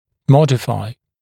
[‘mɔdɪfaɪ][‘модифай]видоизменять, модифицировать